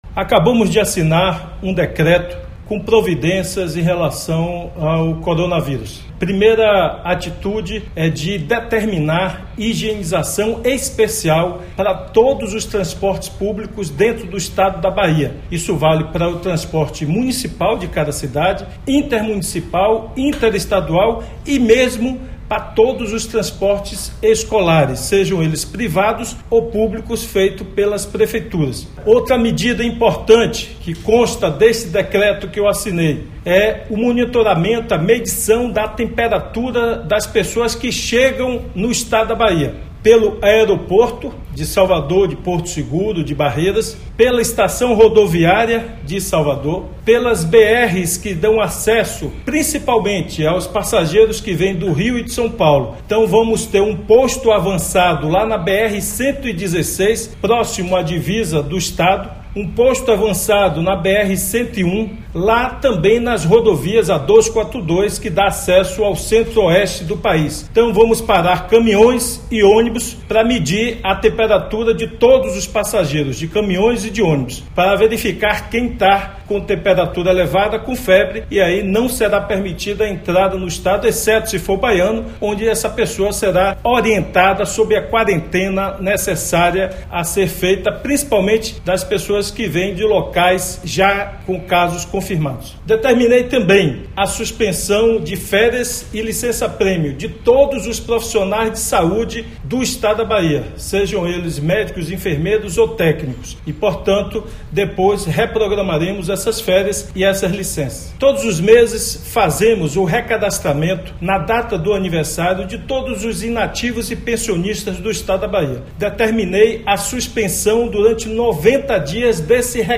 “O objetivo é assegurar a saúde pública, a segurança da saúde das pessoas”, disse Rui Costa em áudio divulgado por sua assessoria.
OUÇA OS ÁUDIOS DO GOVERNADOR RUI COSTA